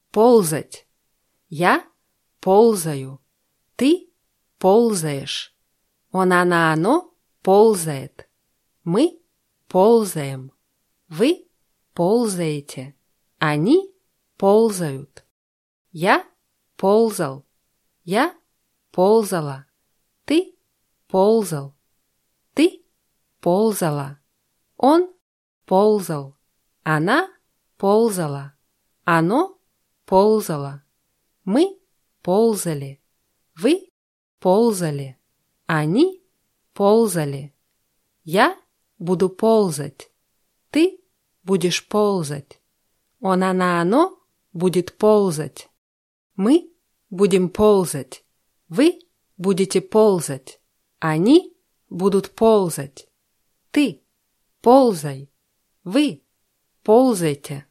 ползать [pólzatʲ]